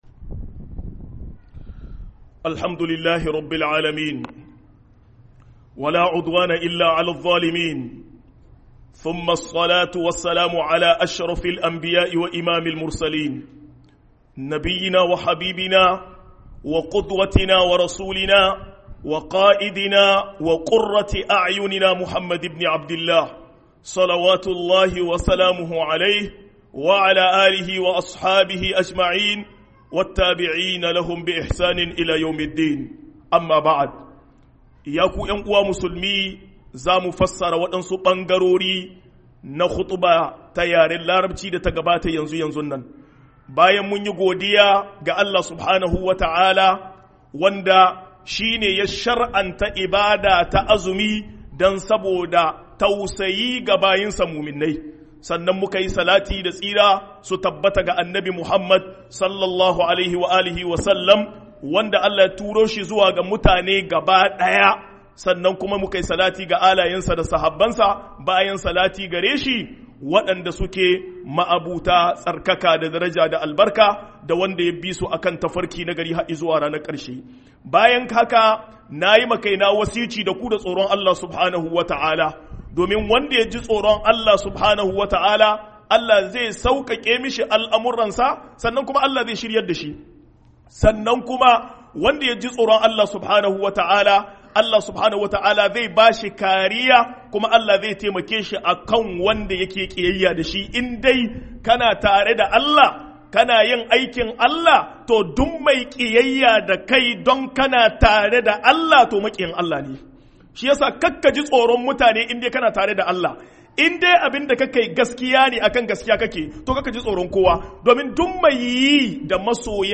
003-Kwadaitarwa game da yin azumi - KHUƊUBAR JUMA’A (HAUSA)